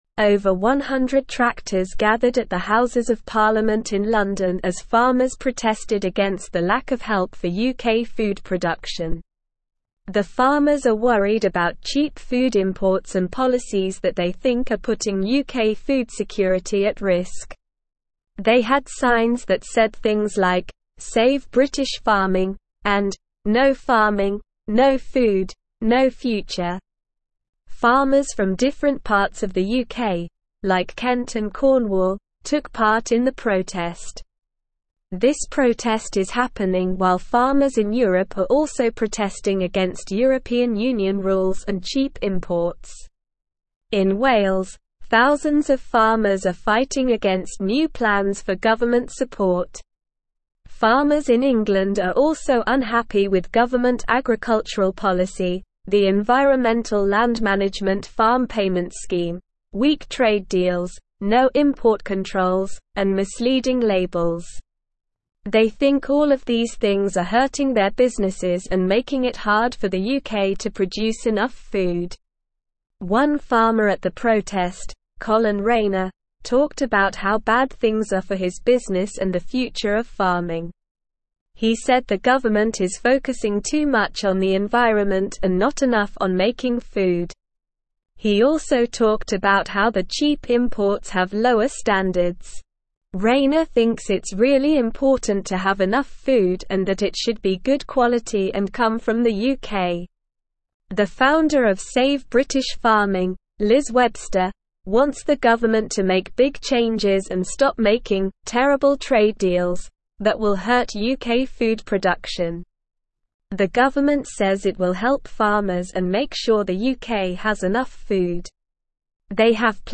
Slow
English-Newsroom-Upper-Intermediate-SLOW-Reading-Farmers-Rally-at-UK-Parliament-to-Save-British-Farming.mp3